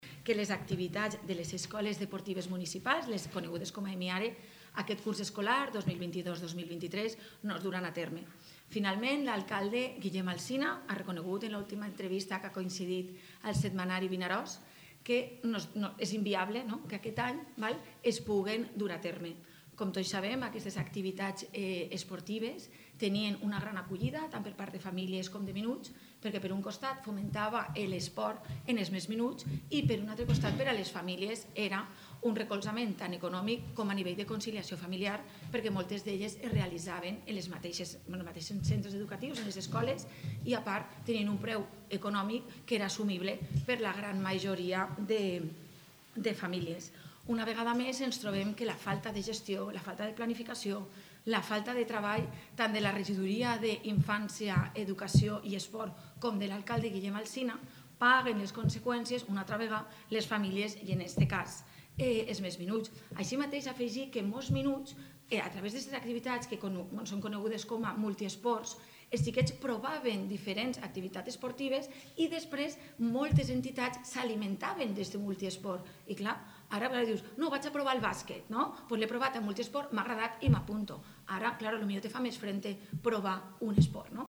Mercedes García, regidora del PP
La regidora del Partit Popular a l’Ajuntament de Vinaròs, Mercedes García, ha comparegut avui en roda de premsa per denunciar que “les famílies de Vinaròs aquest any no podran comptar amb les Escoles Esportives Municipals després de fracassar el govern de Guillem Alsina en la licitació”.